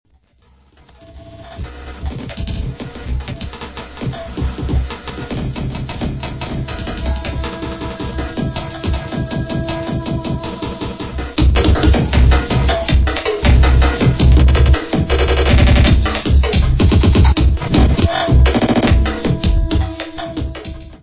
Music Category/Genre:  Pop / Rock